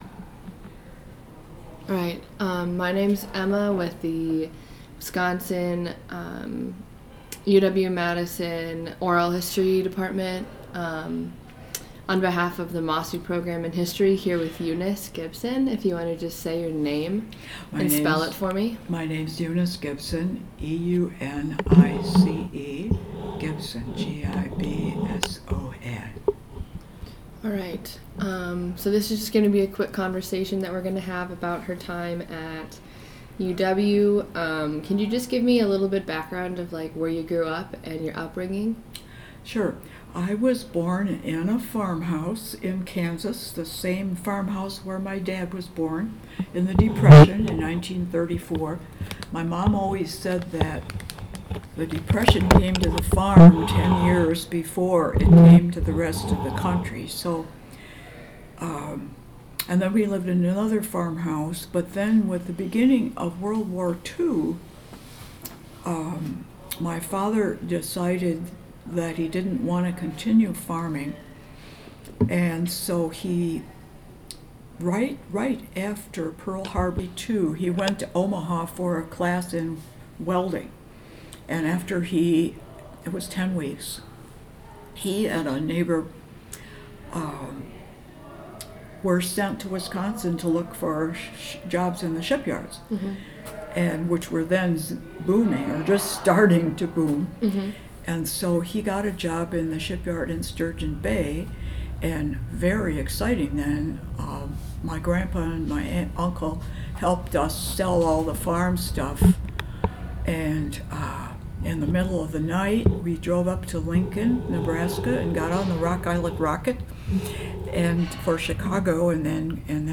Oral History Interview: Eunice Gibson (1704)